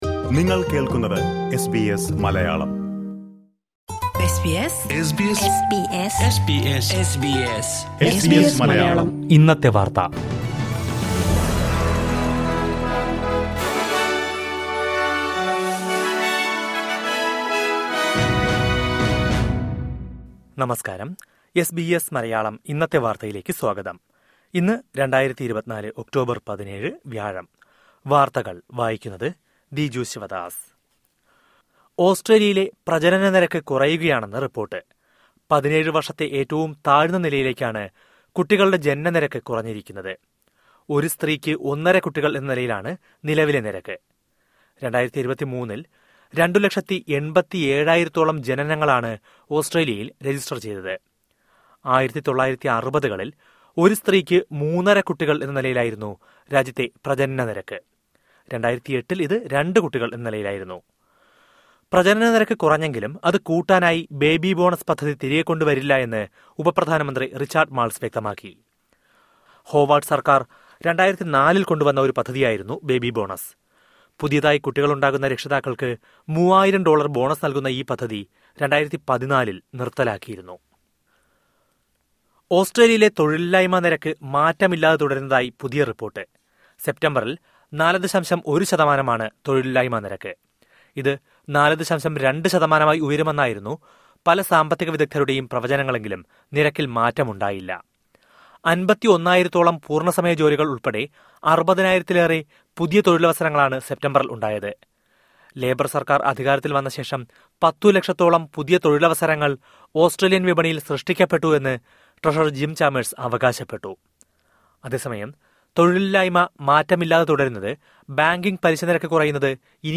2024 ഒക്ടോബര്‍ 17ലെ ഓസ്‌ട്രേലിയയിലെ ഏറ്റവും പ്രധാന വാര്‍ത്തകള്‍ കേള്‍ക്കാം...